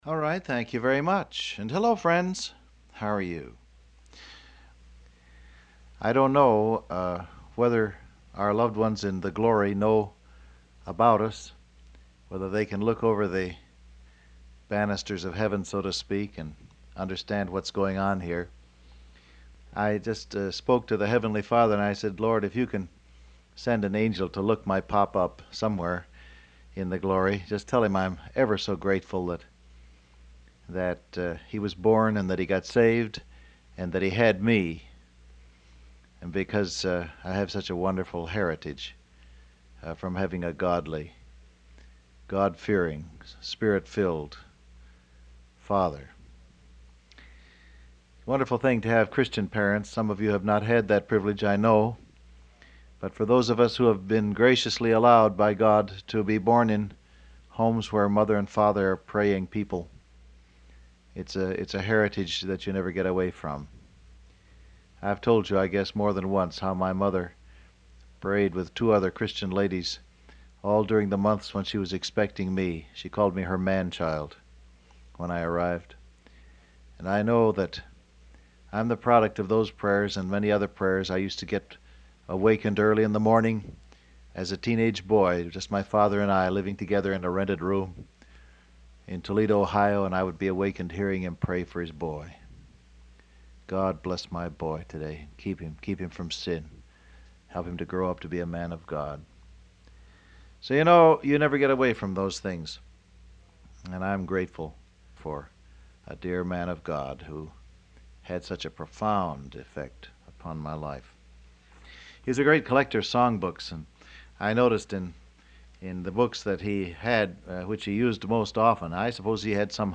Download Audio Print Broadcast #7439 Scripture: Acts 15:30 Topics: Pray , Encourage People , Confirm The Truth In Them Transcript Facebook Twitter WhatsApp Alright, thank you very much.